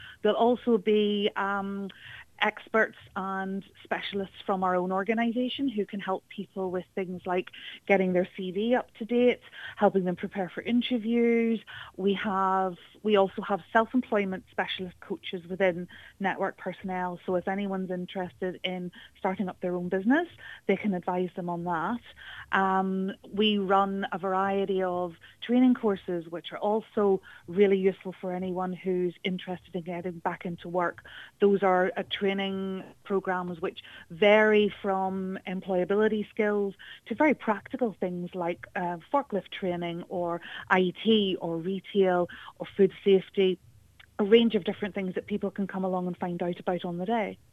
Q Radio spoke with one of their employment coaches